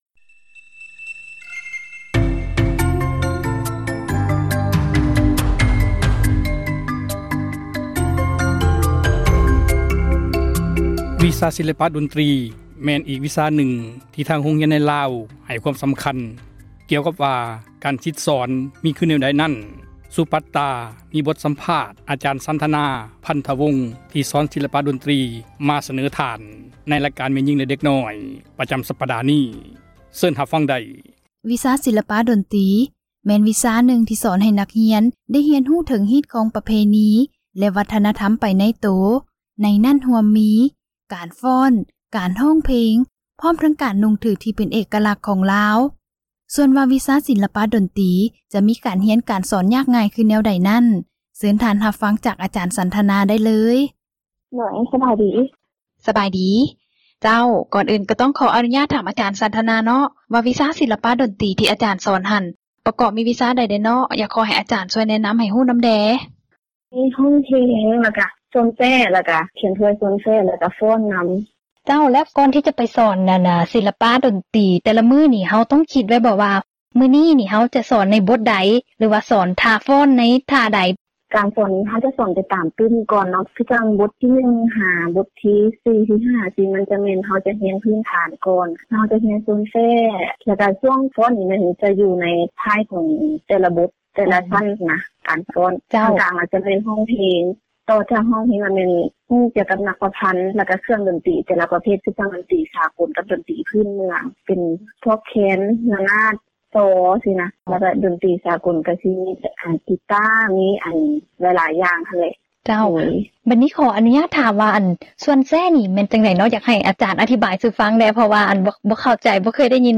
ມີບົດສຳພາດ